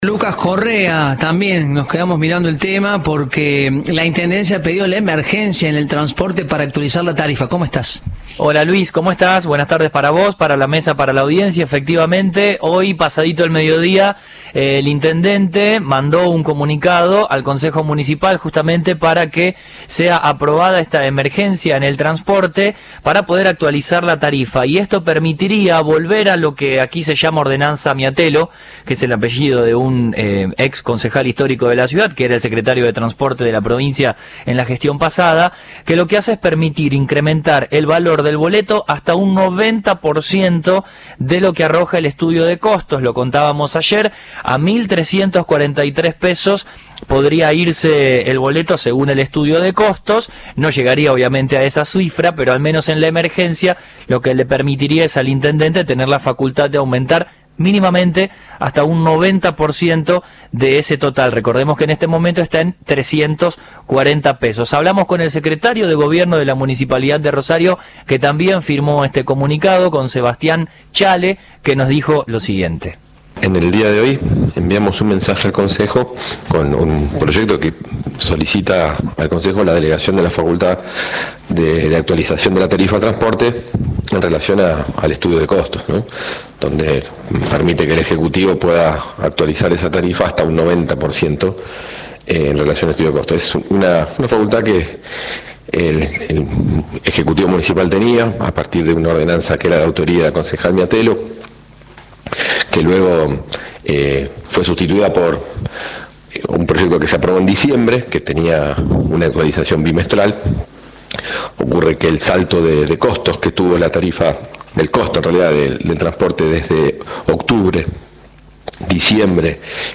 Lo dijo el secretario de Gobierno de Rosario a Cadena 3, luego del pedido por parte de la Intendencia al Concejo para mayores facultades de aumento del boleto.